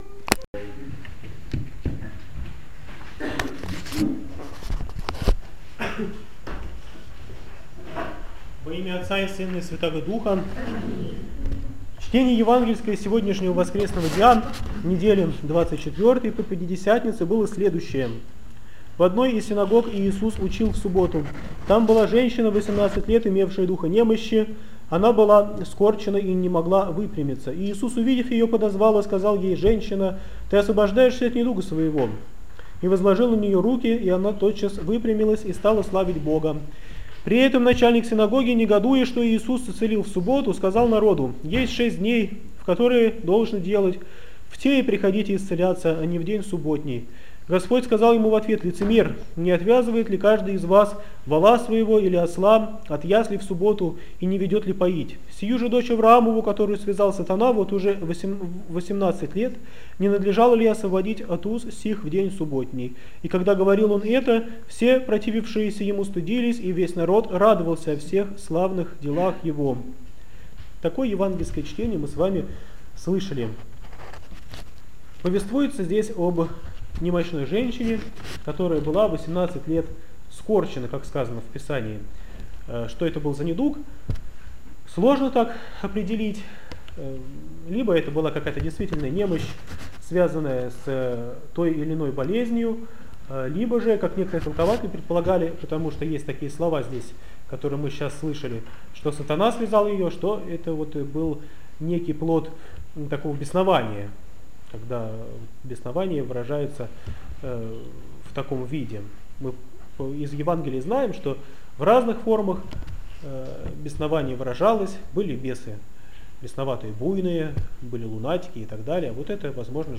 БОГОЛЮБСКИЙ ХРАМ ПОСЕЛОК ДУБРОВСКИЙ - Проповедь в Неделю 24-ю по Пятидесятнице, о скорченной женщине, 2013